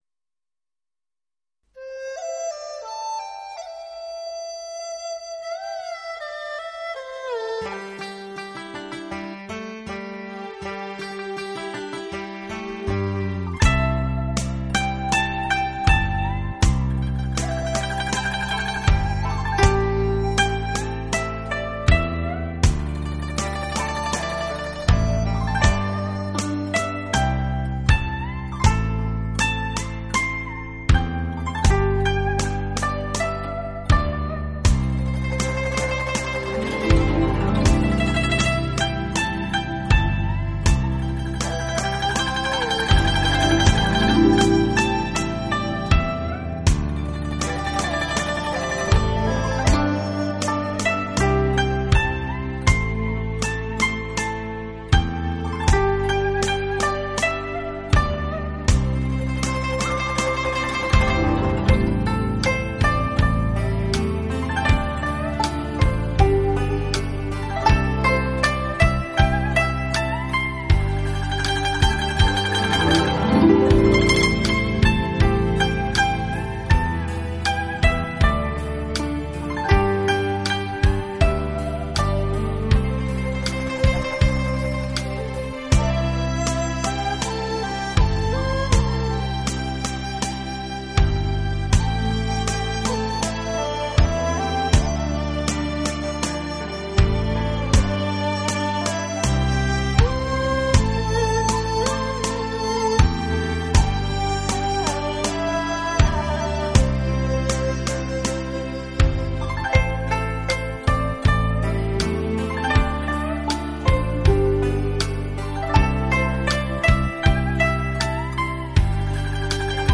类别: 轻音乐
潺流水，丝丝鸟语，静心聆听，让人感觉心灵轻松和欢愉。